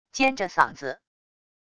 尖着嗓子wav音频